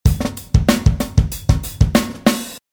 I used it to produce the 7/4 beat in the drum part:
virus-drums-preCyclone.mp3